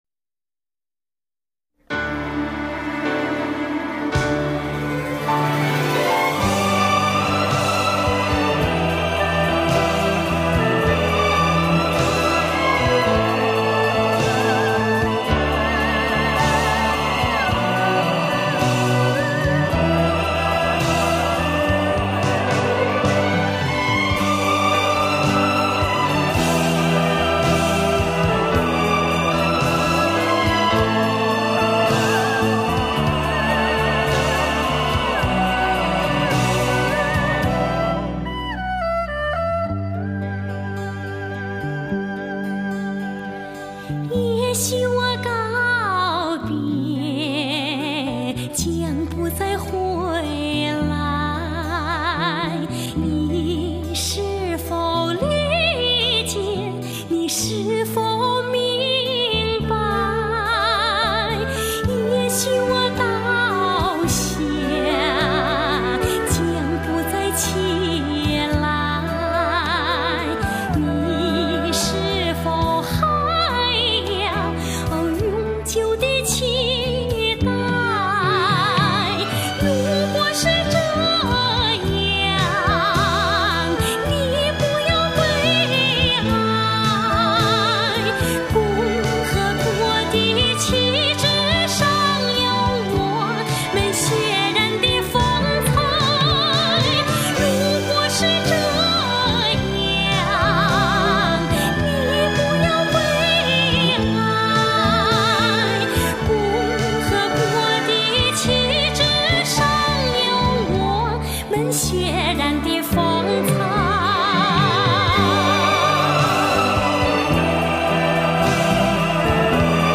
队音乐史上各时期、各个题材，脍炙人口、充满军人豪情又优美动听的军旅歌曲经典。
质朴的情感，奔放的激情，难忘的旋律。